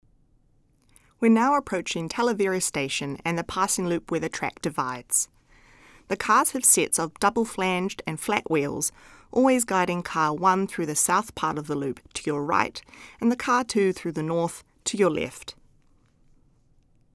Audio Commentary
In the top tunnel after Salamanca.